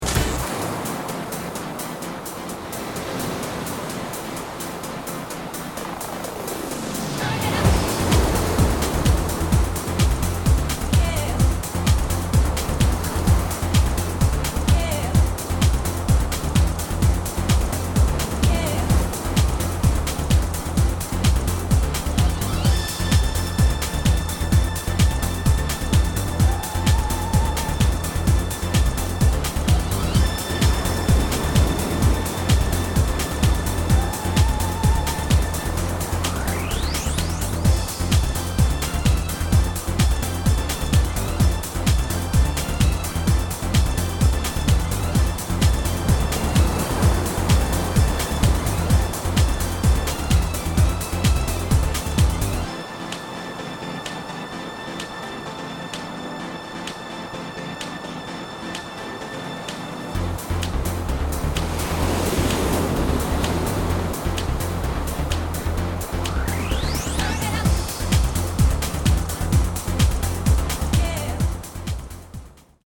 耳に聞こえないサブリミナルメッセージの内容